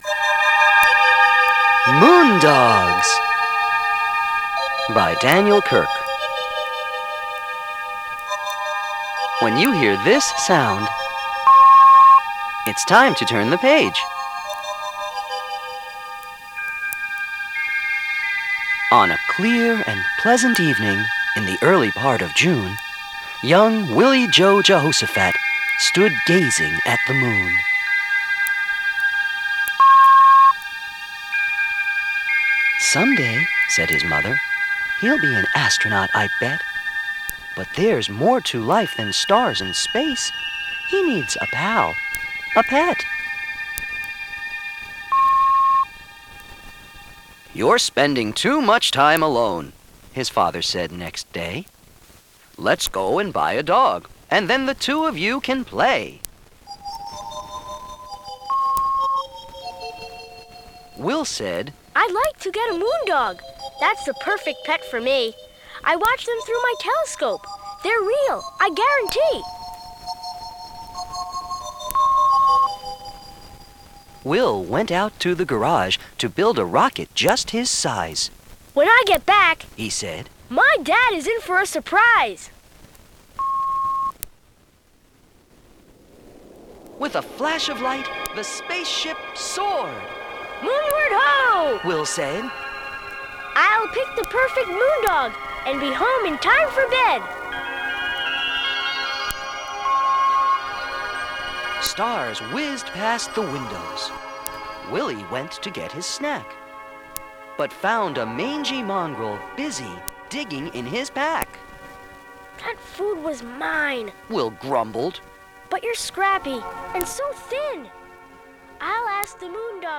DOWNLOAD AUDIO NOVEL